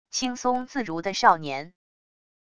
轻松自如的少年wav音频